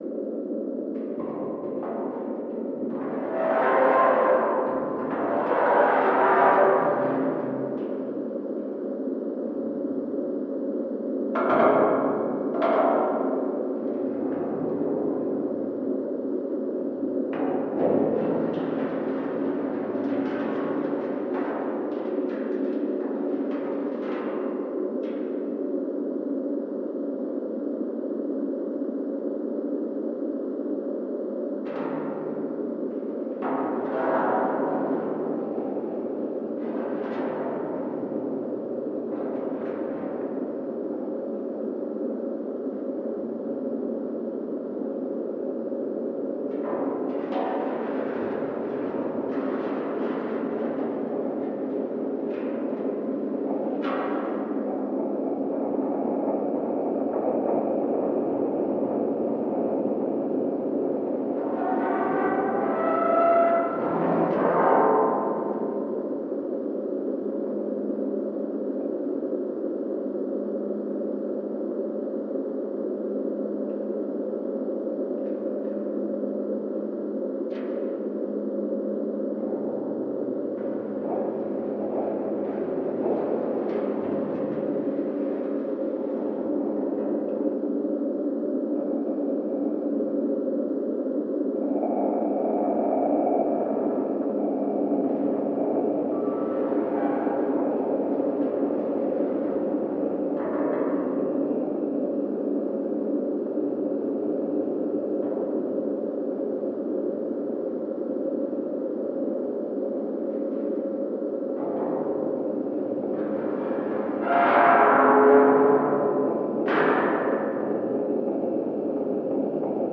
Звуки заброшенной психбольницы или тюрьмы